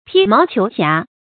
披毛求瑕 注音： ㄆㄧ ㄇㄠˊ ㄑㄧㄡˊ ㄒㄧㄚˊ 讀音讀法： 意思解釋： 見「披毛求疵」。